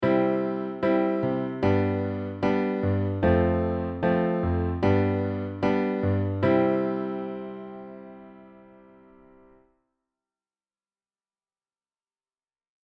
トニックさん→ドミナントさん→サブドミナントさん→ドミナントさんで
トニックさんに戻る世界だね。
すっごく自然で平和な感じだねっ。